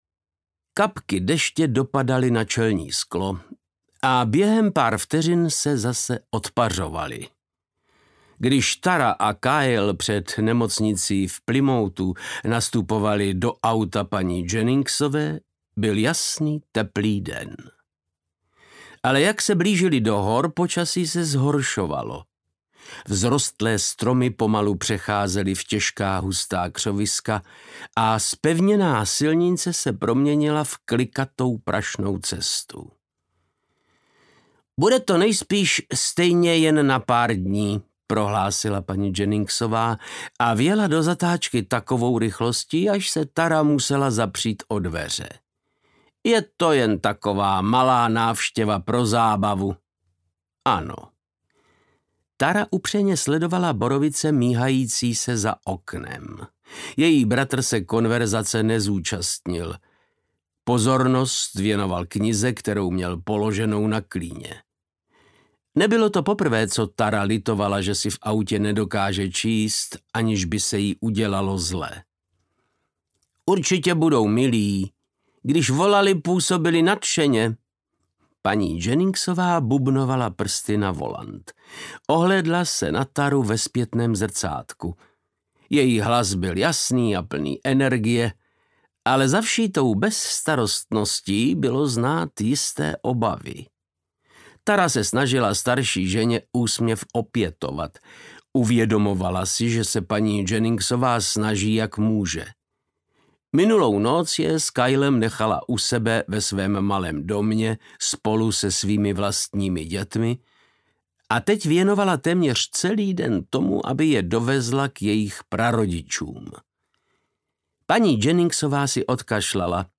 Duchové rodiny Folcroftů audiokniha
Ukázka z knihy
• InterpretVáclav Knop